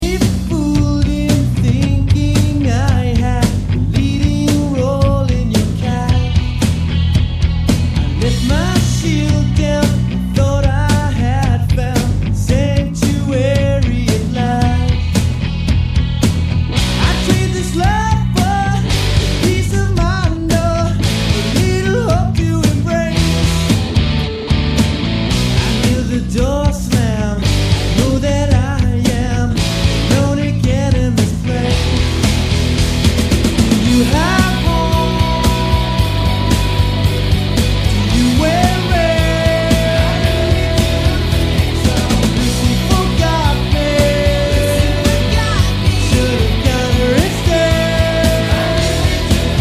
all-out rockers